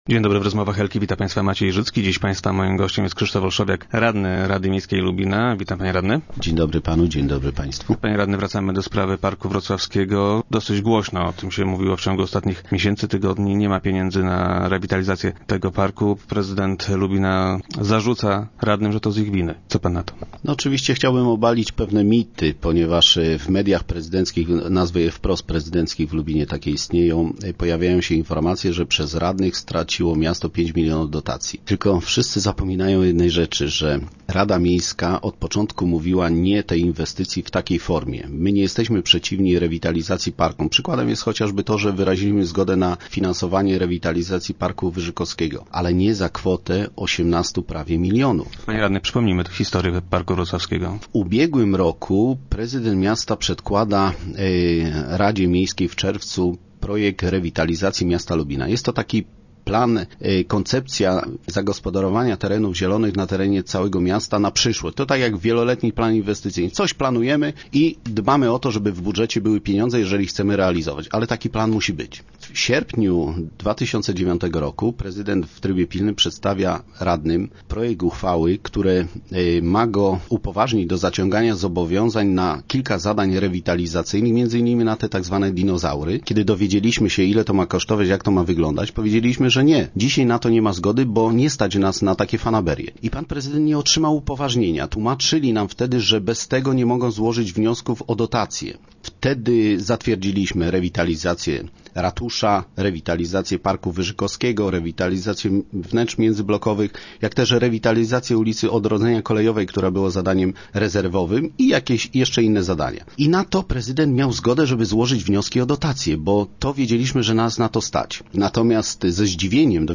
Zdaniem radnych, prezydent poświadczył nieprawdę składając wniosek o unijną dotację. Gościem Rozmów Elki był radny Krzysztof Olszowiak.